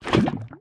Minecraft Version Minecraft Version snapshot Latest Release | Latest Snapshot snapshot / assets / minecraft / sounds / item / bucket / fill_lava2.ogg Compare With Compare With Latest Release | Latest Snapshot
fill_lava2.ogg